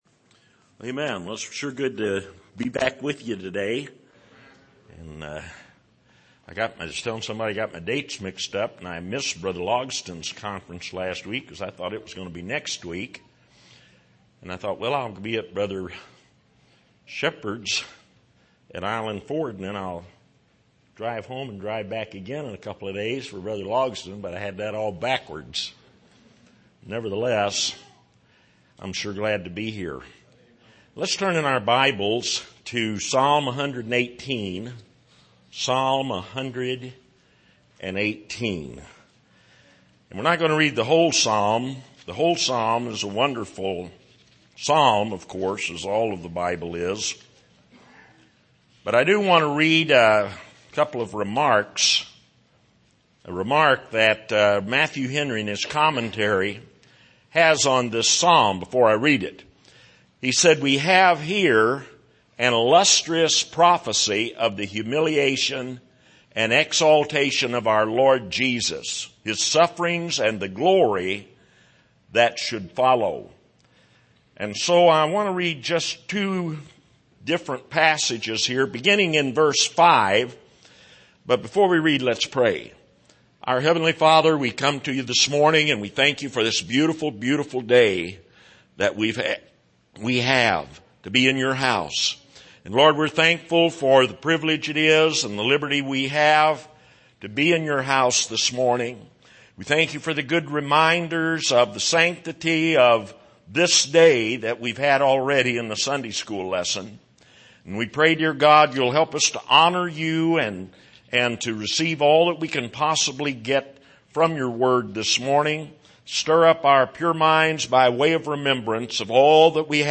Miscellaneous Passage: Psalm 118:5-8, 21-24 Service: Sunday Morning